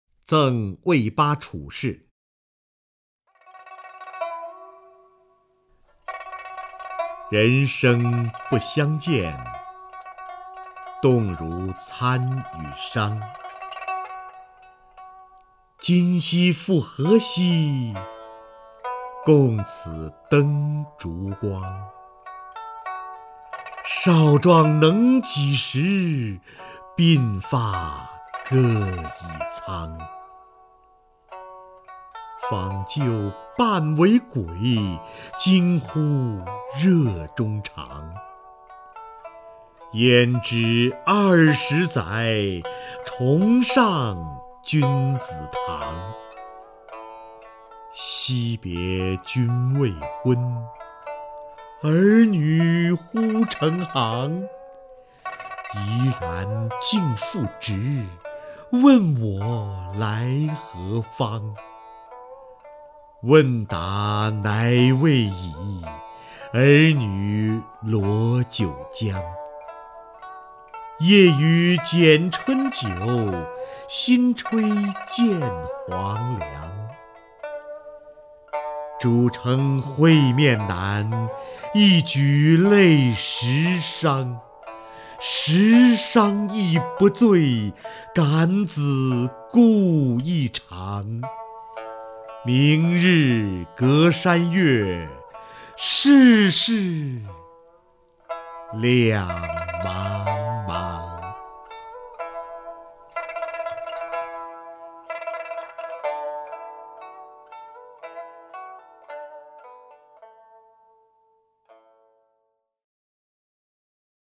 瞿弦和朗诵：《赠卫八处士》(（唐）杜甫)　/ （唐）杜甫
名家朗诵欣赏 瞿弦和 目录